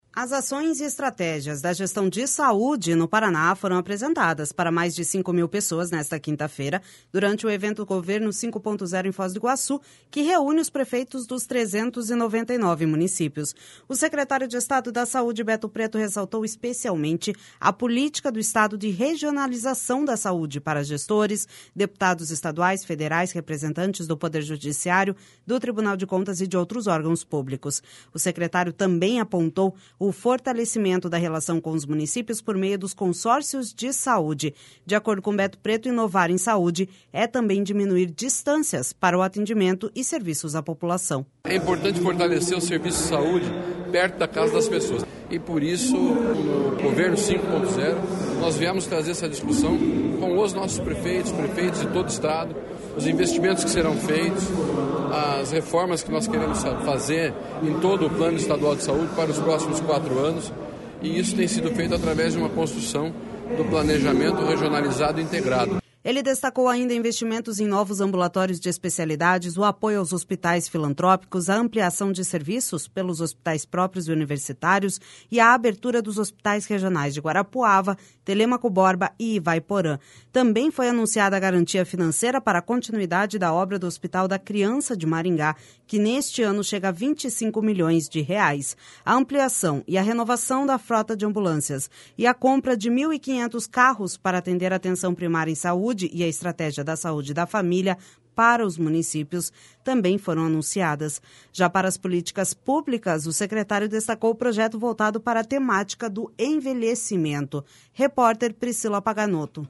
As ações e estratégias da gestão de saúde no Paraná foram apresentadas para mais de cinco mil pessoas nesta quinta-feira, durante o evento Governo 5.0, em Foz do Iguaçu, que reúne os prefeitos dos 399 municípios.
De acordo com Beto Preto, inovar em saúde é também diminuir distâncias para o atendimento e serviços à população.// SONORA BETO PRETO//Ele destacou, ainda, investimentos em novos ambulatórios de especialidades, o apoio aos hospitais filantrópicos, a ampliação de serviços pelos hospitais próprios e universitários e a abertura dos Hospitais Regionais de Guarapuava, Telêmaco Borba e Ivaiporã.